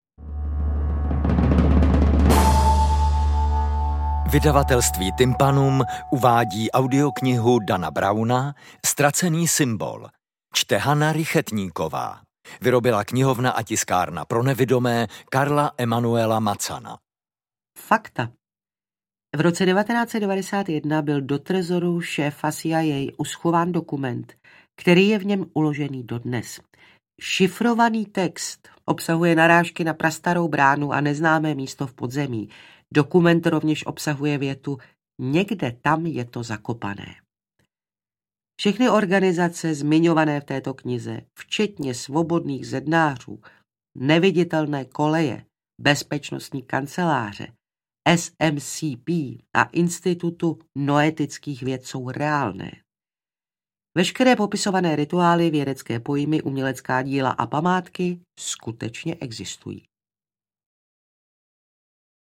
Ztracený symbol Audiokniha